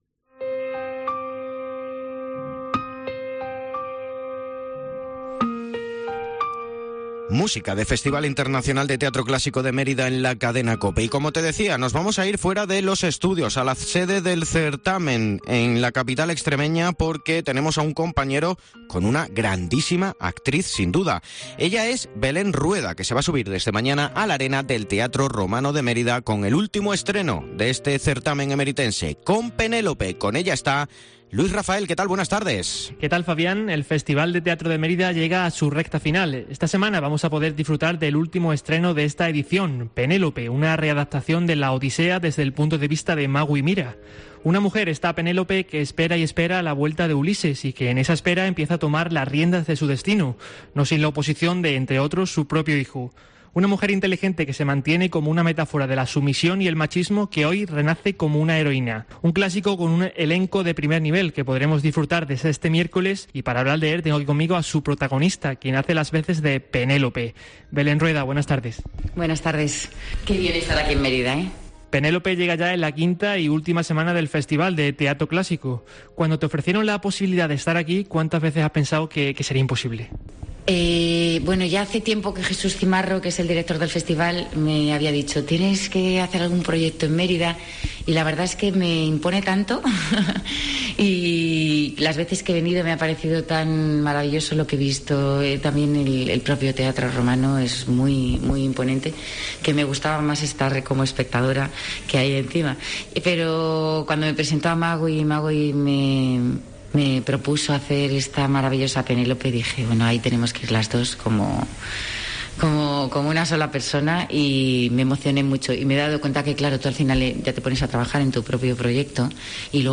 Belén Rueda atiende a los micrófonos de 'Cope Extremadura' antes del estreno de 'Penélope'.